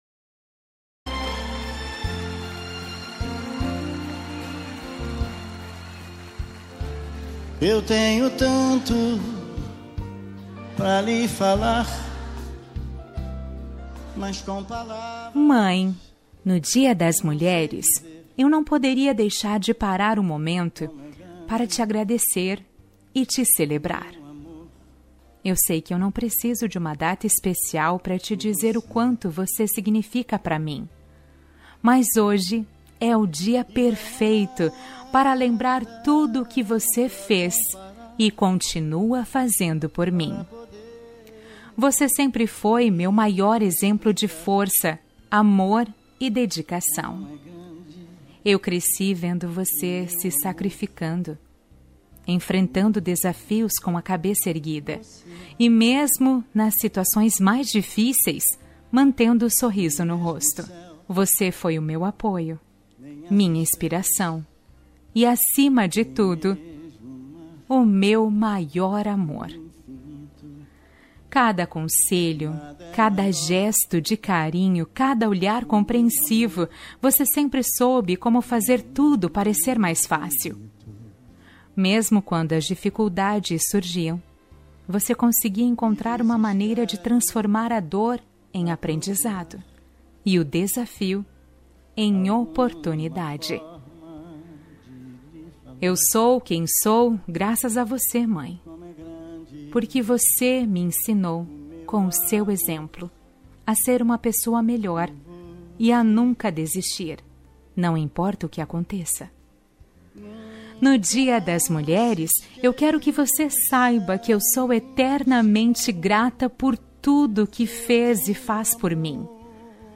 Dia da Mulher – Mãe – Feminina – Cód: 690707